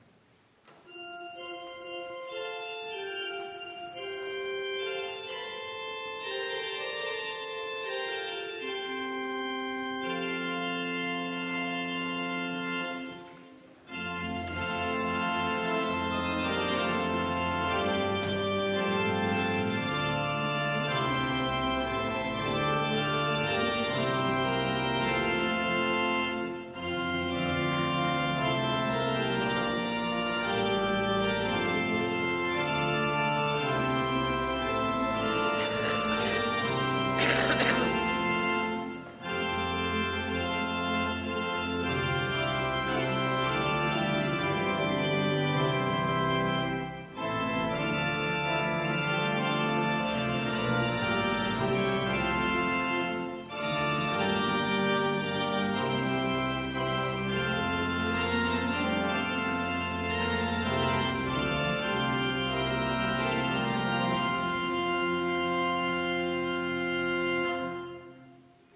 Gottesdienst am 13.06.2021